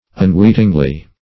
[1913 Webster] -- Un*weet"ing*ly , adv.